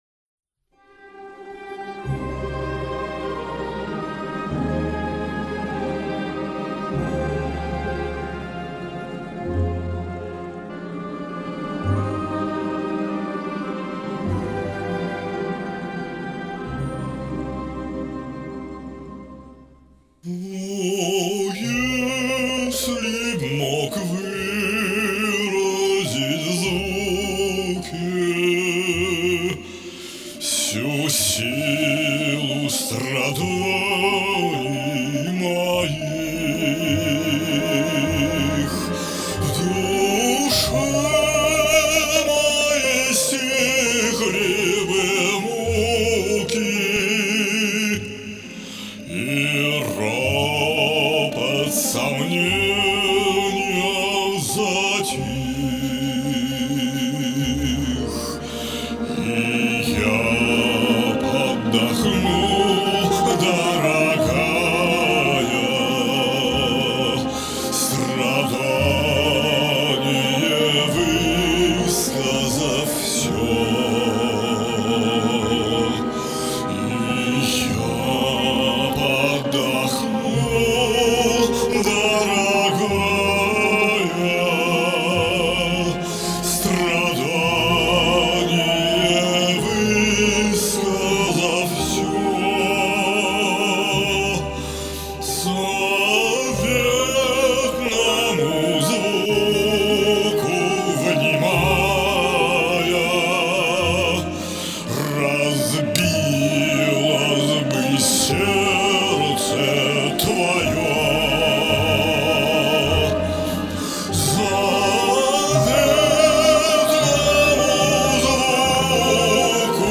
экспрессия, сила, страсть....